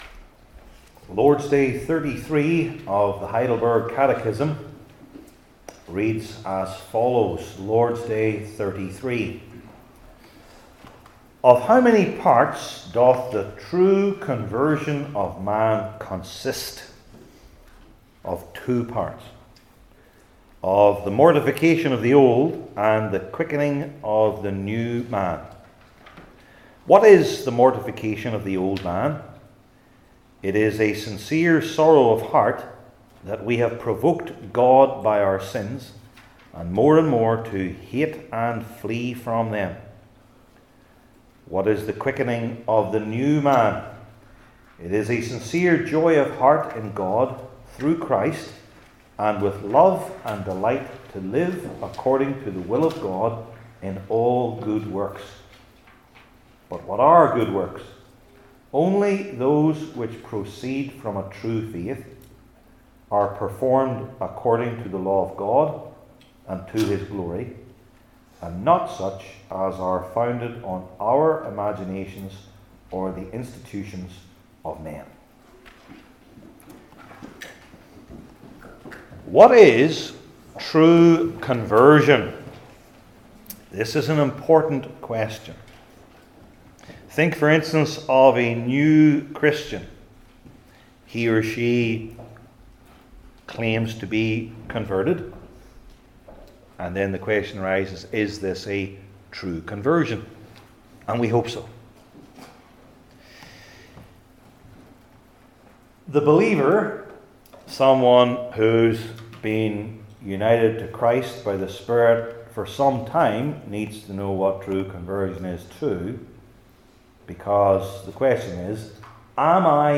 Heidelberg Catechism Sermons I. The First Part II.